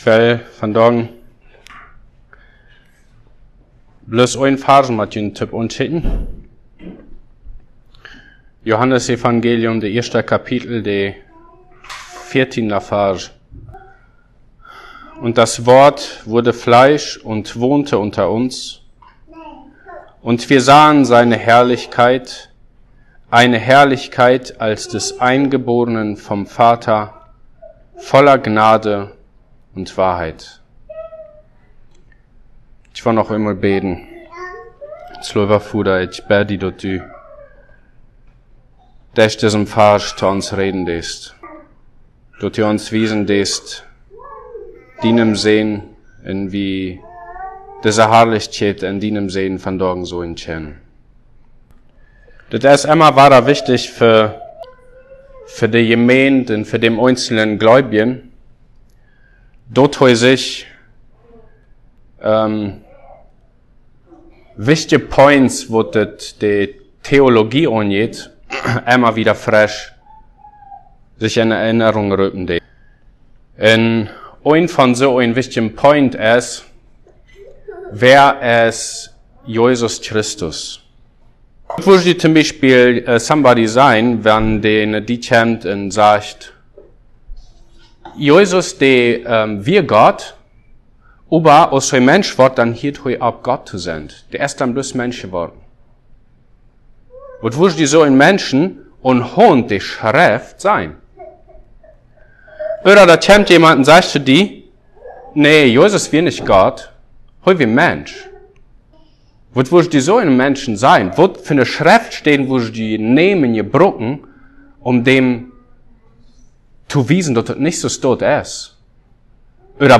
Passage: John 1:14 Service Type: Sunday Plautdietsch « Jie Rikji en Jietsji